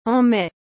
en maiアン メ